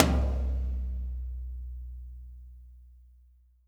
-TOM 2P   -L.wav